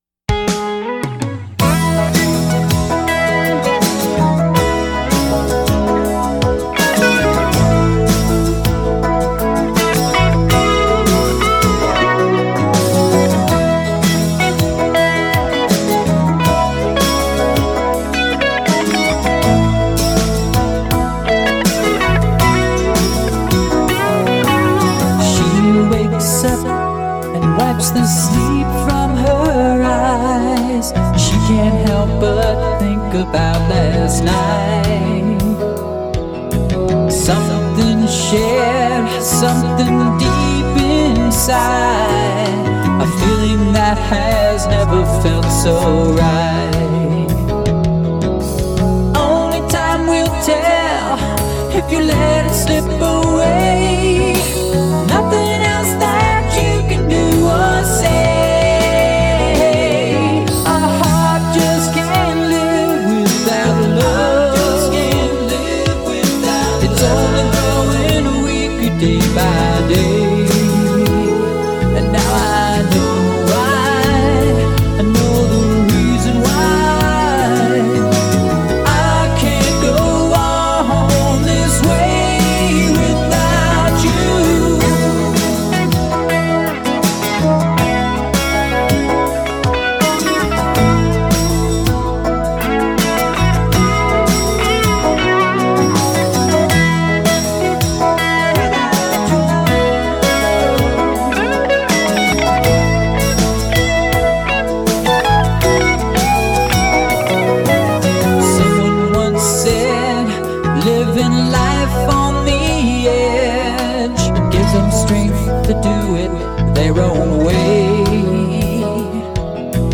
Recorded at Rabid Ears Studio - Lancaster, Pa.